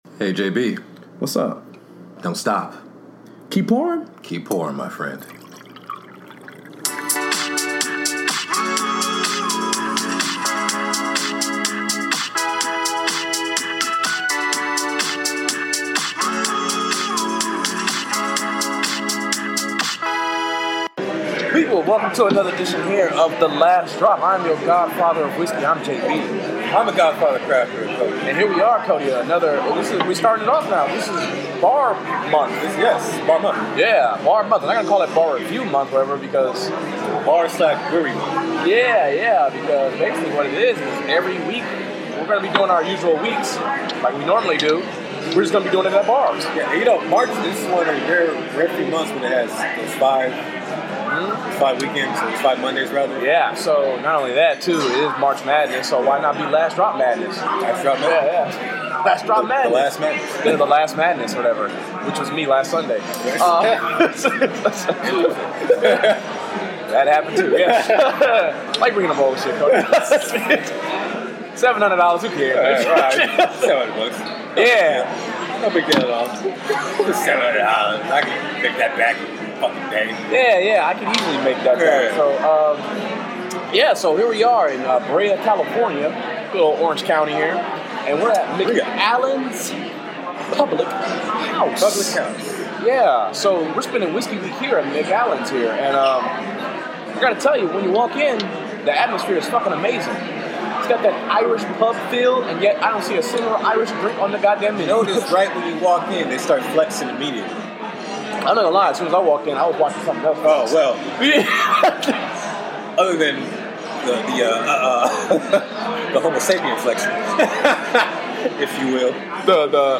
kick off Last Drop Madness at Macallan's Public House for whisky week. 1 glass just isn't enough to kick off the month of March so we both take flight with whisky flights.